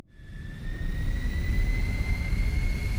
Engine 6 Start.wav